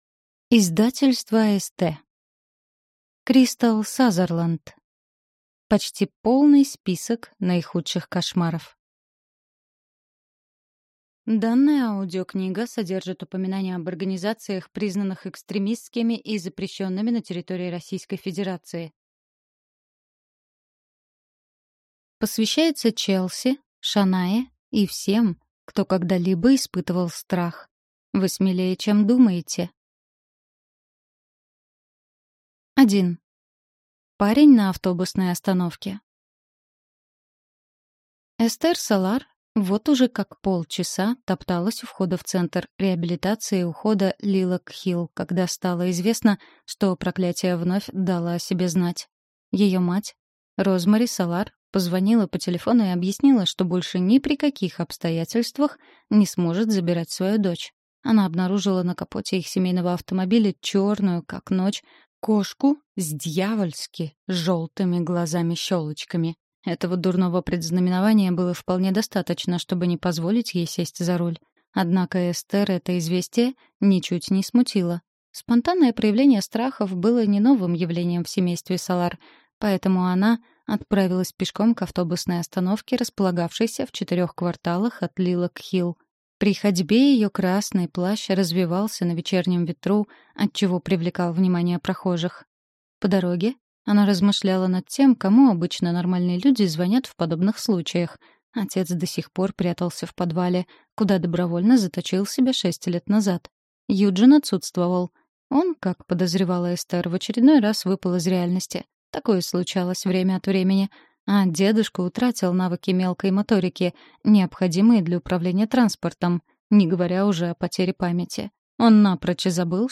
Аудиокнига Почти полный список наихудших кошмаров | Библиотека аудиокниг